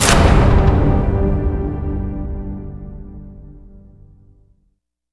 LC IMP SLAM 6.WAV